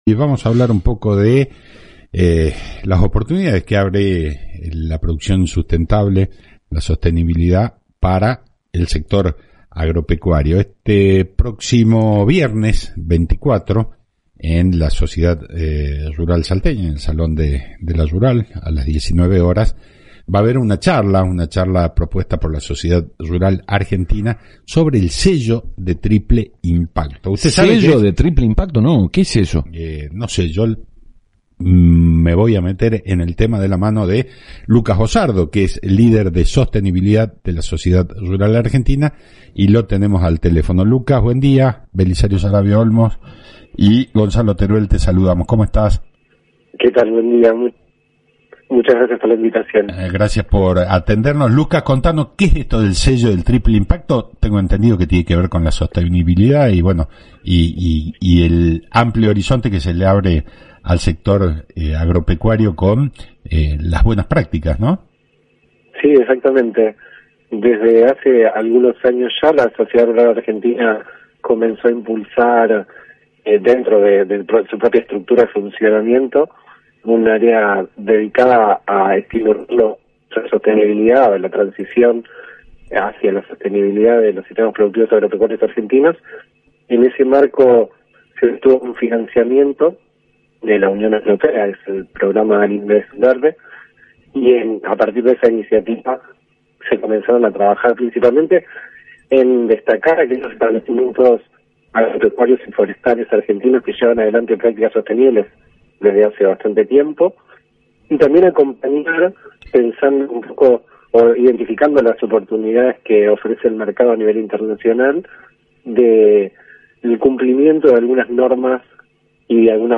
en el programa Claves del Campo (AM 840 Radio Salta)